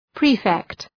Προφορά
{‘pri:fekt}